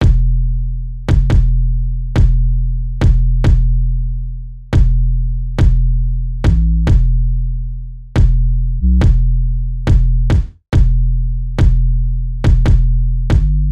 大鼓 808
描述：陷阱鼓。一个不错的循环，这个循环（3个），是808。